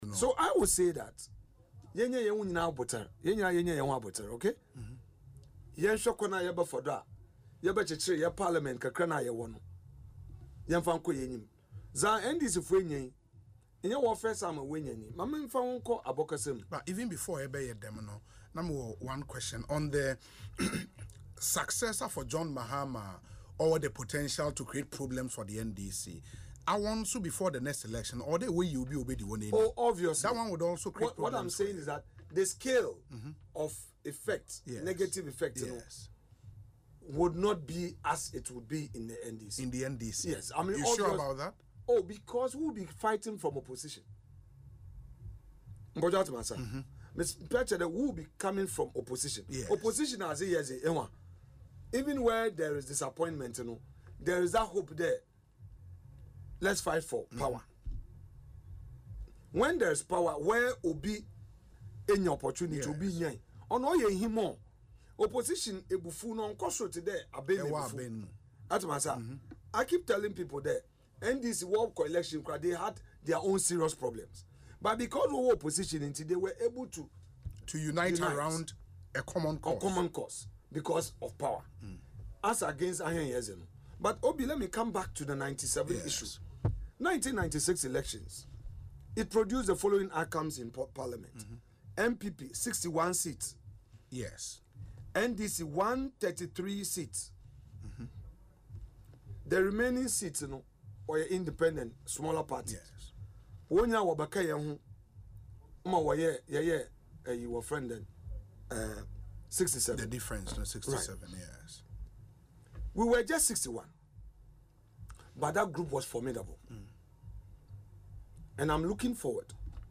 Speaking in an interview on Asempa FM’s Ekosii Sen show, he stated that the NPP is leaving the NDC with a strong economy.